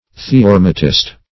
Theorematist \The`o*rem"a*tist\, n.